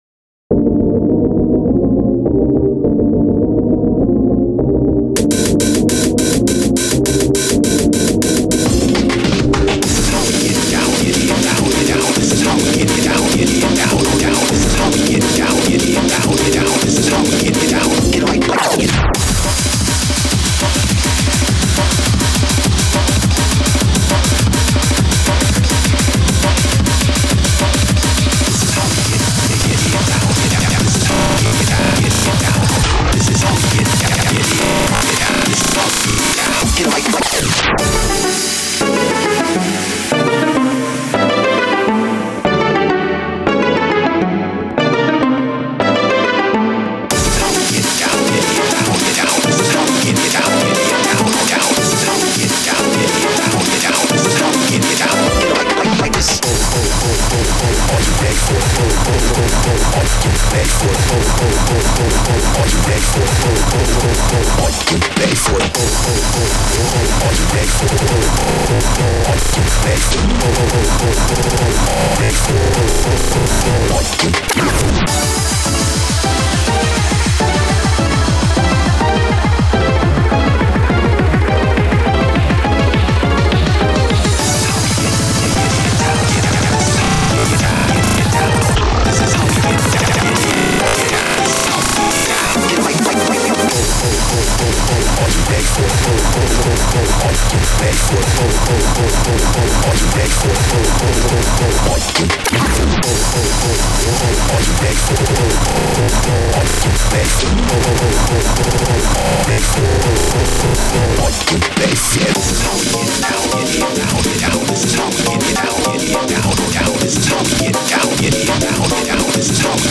Freeform HARD CORE